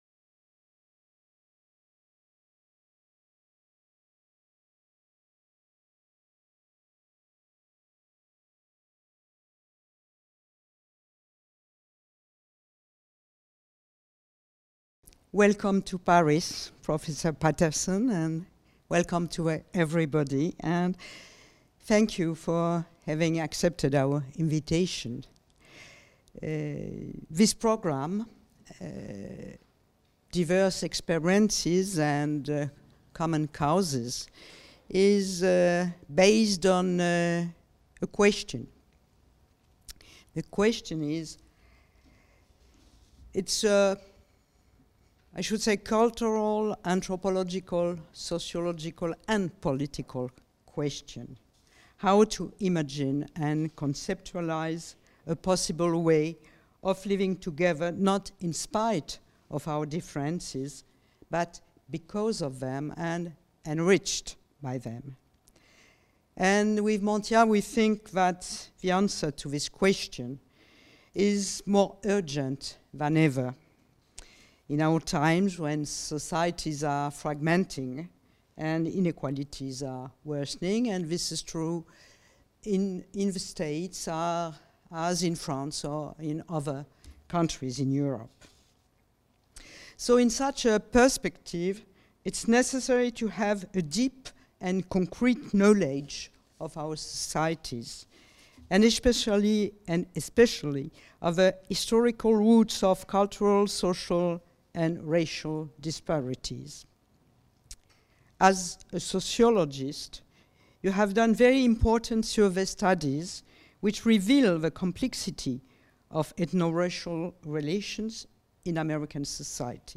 With Orlando Patterson, Sociologist, Professor at Harvard Orlando Patterson has conducted extensive research on ethno-racial relations and the social and cultural effects of poverty among black youth in the United States. The analyses and proposals contribute to public debate on these issues.